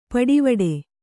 ♪ paḍivaḍe